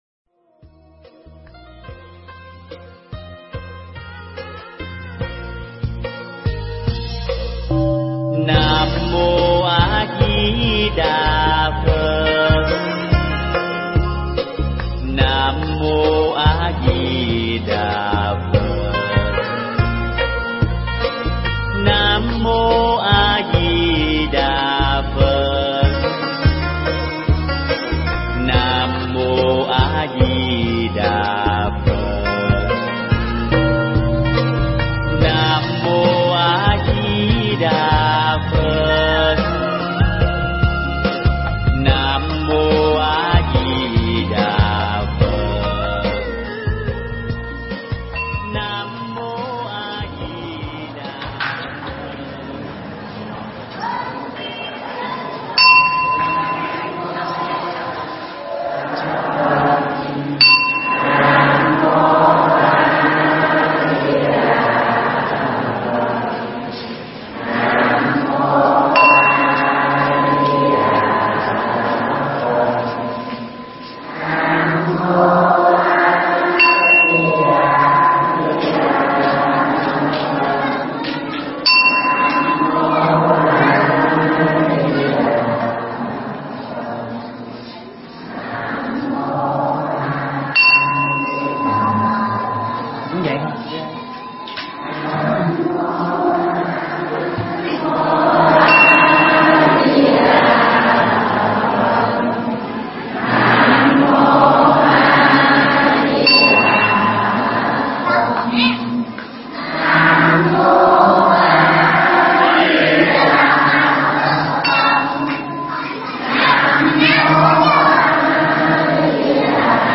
Tải mp3 Pháp Thoại Gieo Giống Bồ Đề